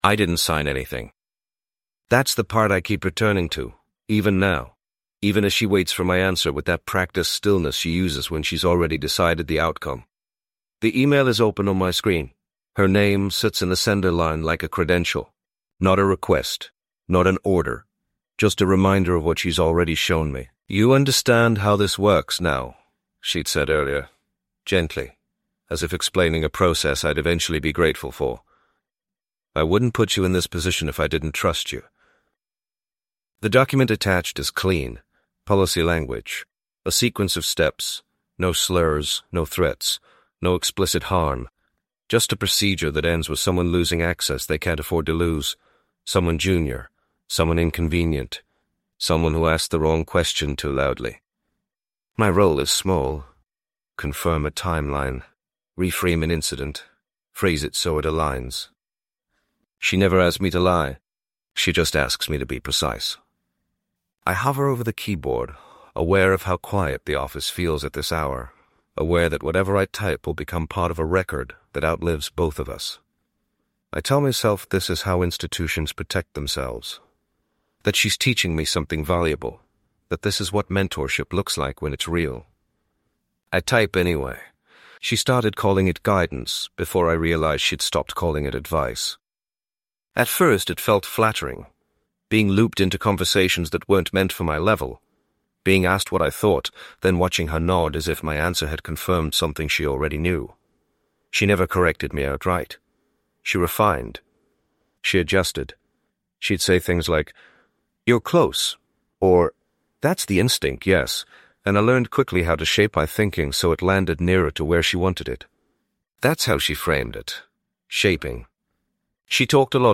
Told from the perspective of a man who never initiates harm but steadily enables it, this micro-drama captures the moment advice becomes instruction—and instruction becomes damage.
No raised voices.